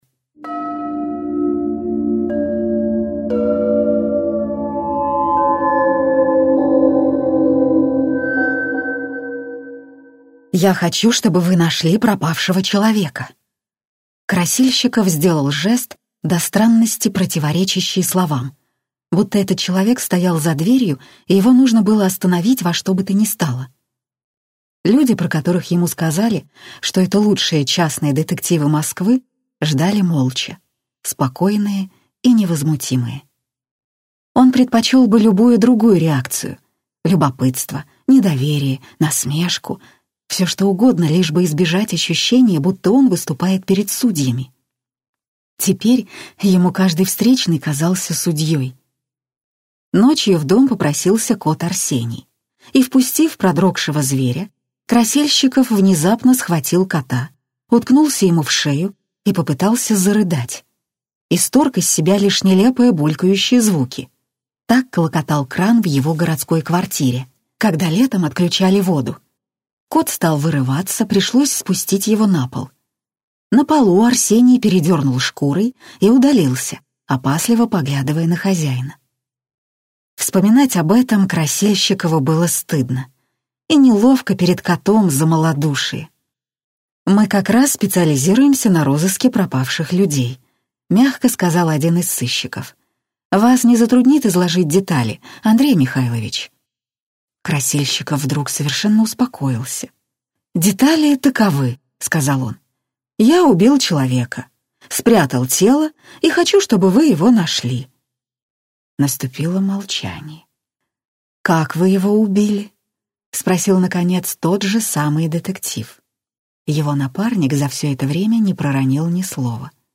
Аудиокнига След лисицы на камнях - купить, скачать и слушать онлайн | КнигоПоиск